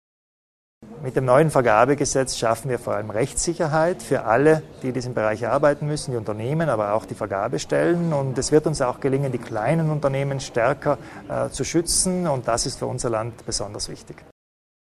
Landeshauptmann Kompatscher zum neuen Vergabegesetz